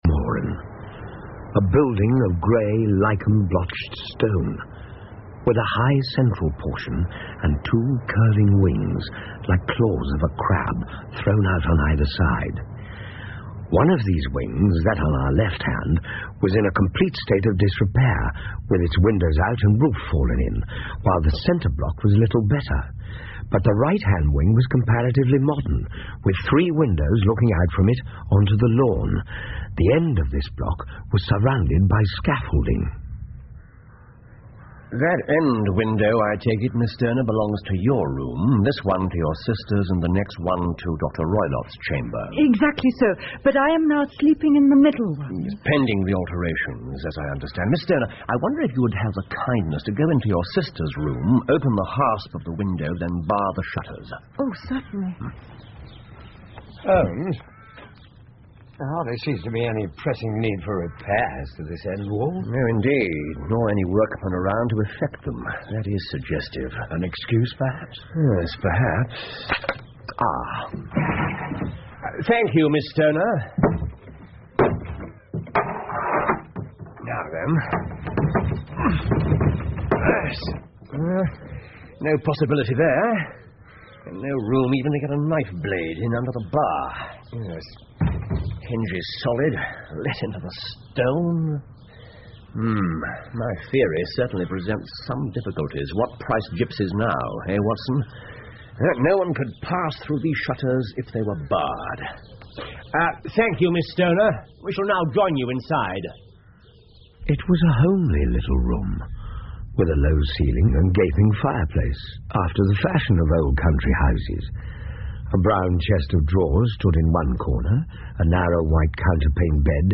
福尔摩斯广播剧 The Speckled Band 6 听力文件下载—在线英语听力室